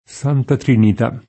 trinit#+] s. f. — sempre con T- maiusc. la T. per antonomasia, la santissima (o Santissima) T.Santa Trinita [